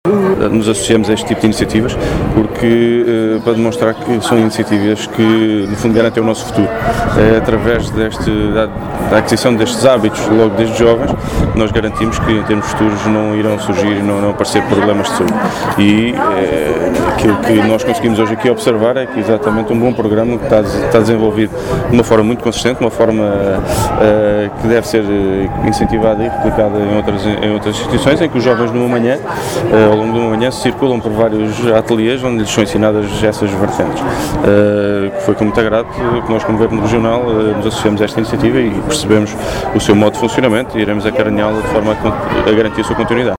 Luís Cabral falava na I Feira da Saúde, que se realizou na Escola Básica Integrada Rui Galvão de Carvalho, na vila de Rabo de Peixe, ilha de São Miguel, sob o lema “Saúde em Movimento”.